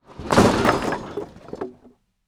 DEMOLISH_Wood_Metal_stereo.wav